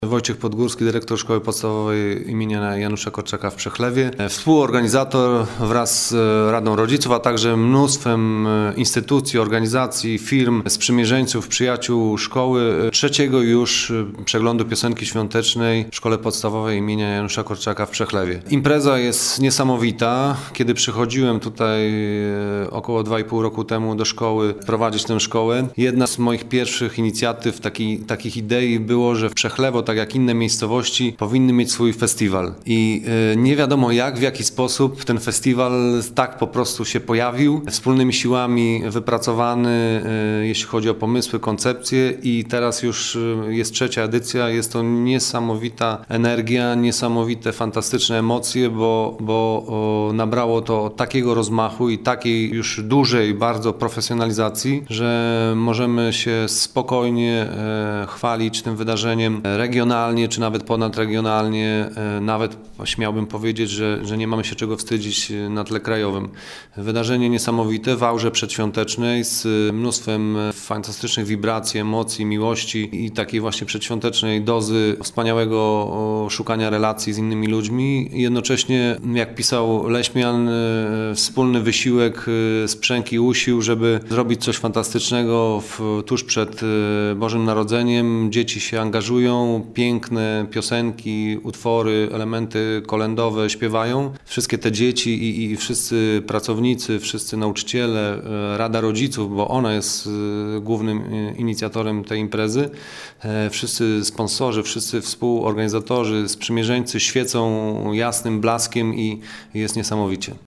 Aż 350 dzieci wzięło udział w tegorocznym Przeglądzie Piosenki Świątecznej w Przechlewie.
Na scenie prezentują się nie tylko dzieci z miejscowej szkoły, ale także rodzice.